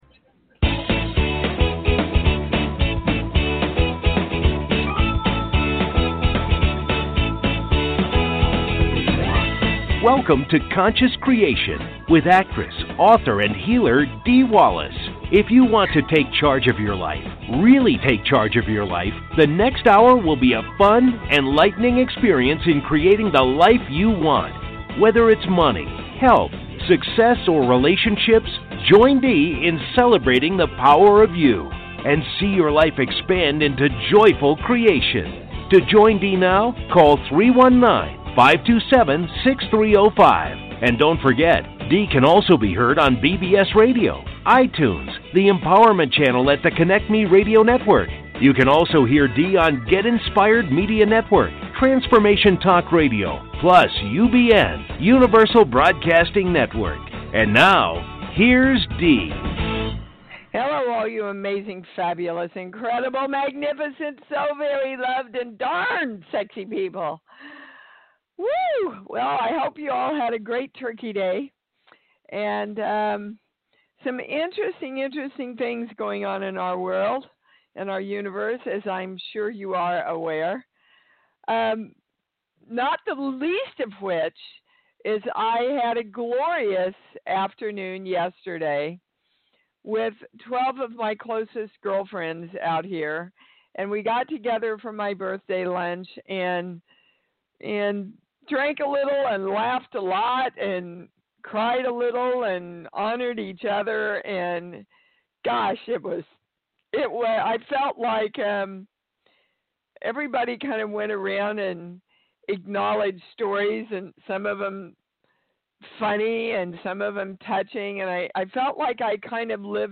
Talk Show Episode, Audio Podcast, Conscious Creation and with Dee Wallace on , show guests , about Spiritual Readings,Core Truths,Balanced Life,Energy Shifts,Spirituality,Spiritual Archaeologist,Core Issues,Spiritual Memoir,Healing Words,Consciousness, categorized as Kids & Family,Paranormal,Philosophy,Spiritual,Access Consciousness,Medium & Channeling,Psychic & Intuitive